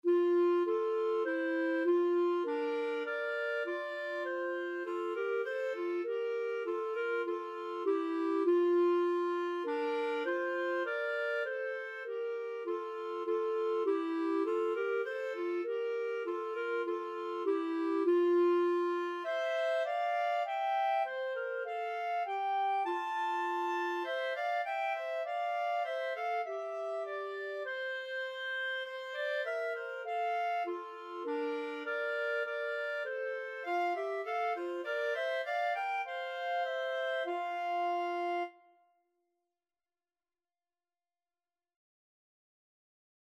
Free Sheet music for Clarinet Duet
4/4 (View more 4/4 Music)
F major (Sounding Pitch) G major (Clarinet in Bb) (View more F major Music for Clarinet Duet )
Traditional (View more Traditional Clarinet Duet Music)